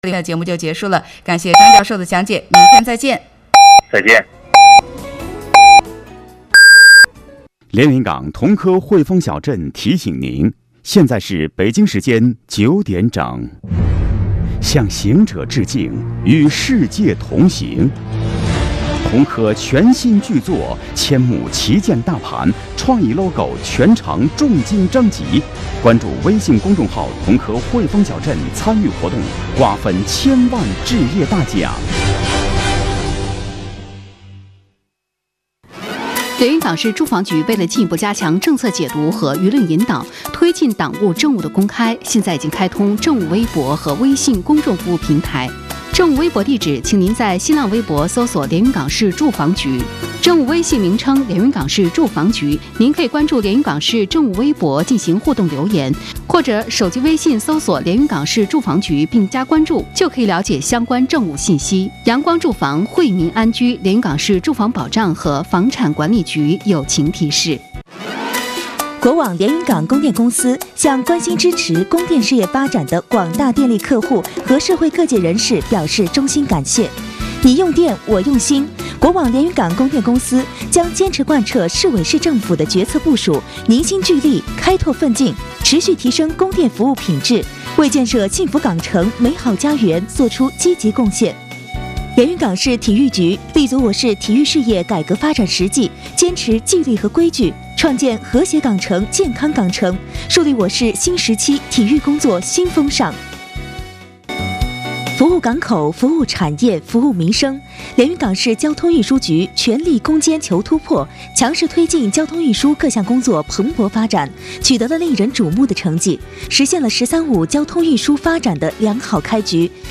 行 风 热 线 2017年7月行风热线上线领导：副局长 叶磊明
特邀嘉宾 副局长 叶磊明